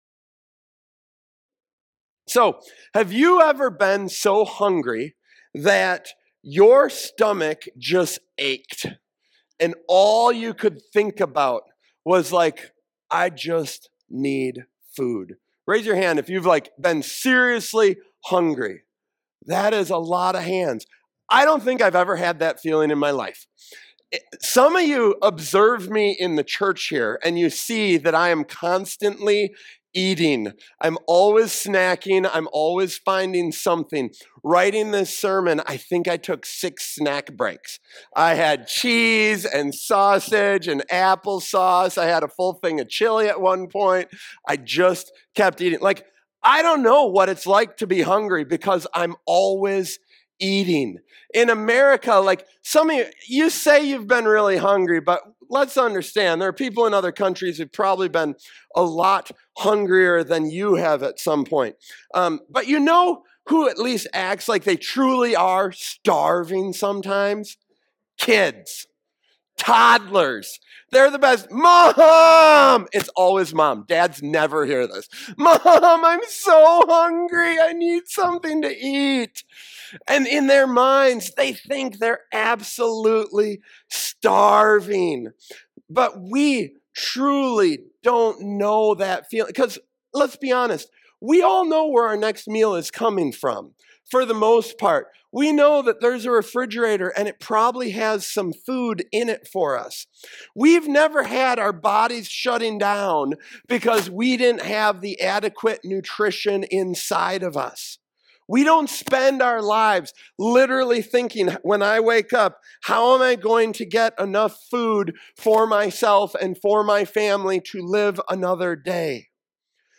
Worship I AM 1 Samuel Watch Listen Save This sermon examines Jesus’ statement in John 6:35: “I am the bread of life,” drawing connections between physical hunger and spiritual longing.